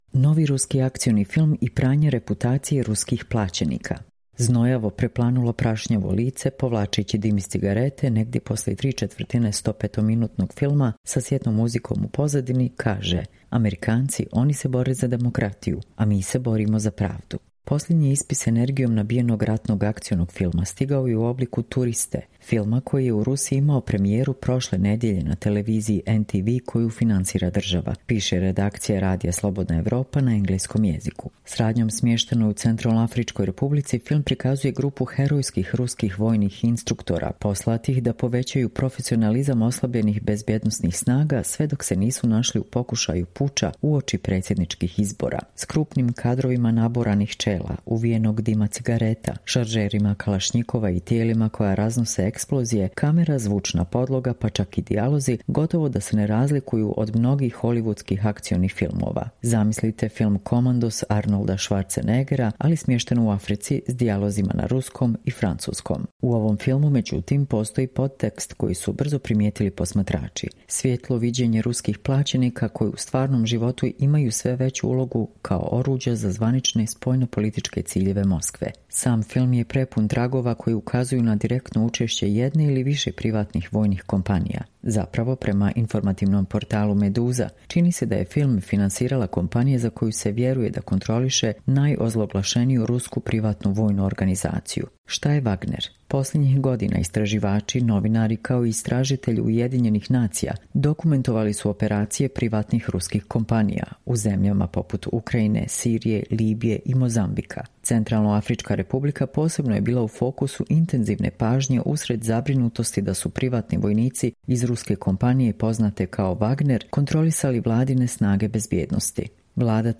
Čitamo vam: Novi ruski akcioni film i pranje reputacije ruskih plaćenika